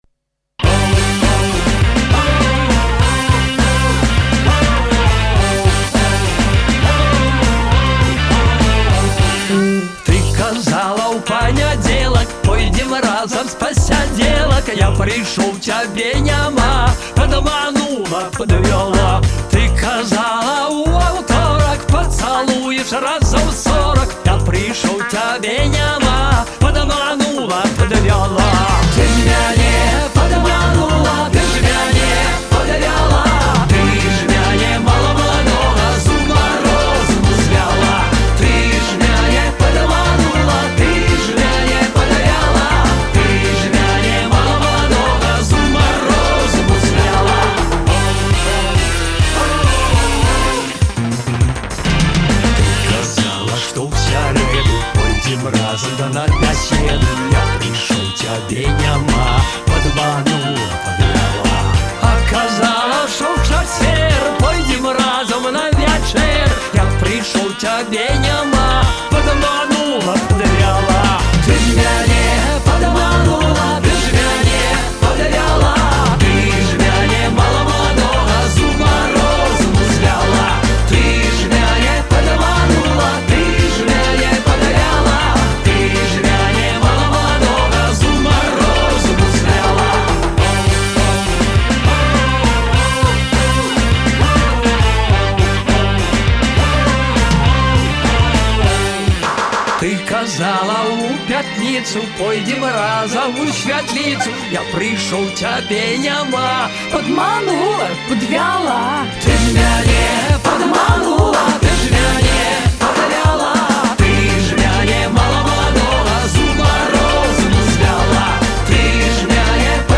народная основа и ультрасовременное воплощение
юморной, с хитрецой